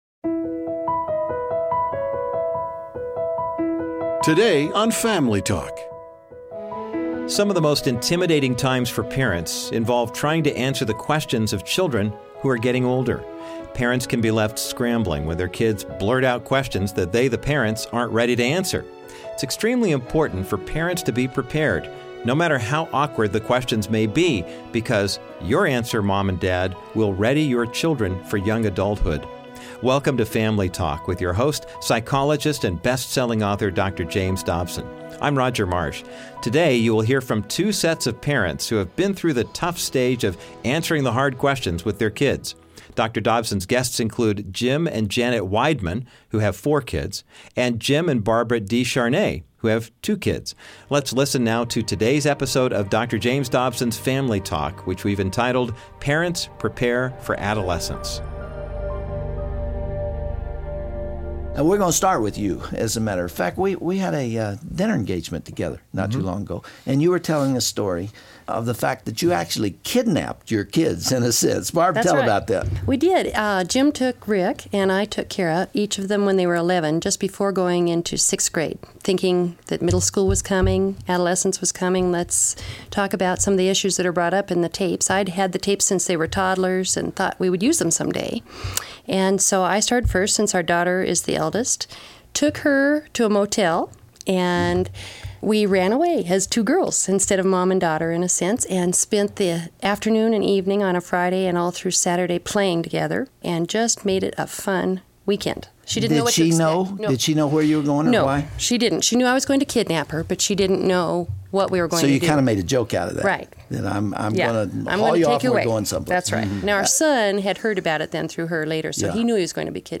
One of the most overwhelming phases a parent goes through with young children is answering their tough questions. Dr. Dobson will talk with two sets of parents about how they handled The Talk with their kids and addressed other questions they had about growing up.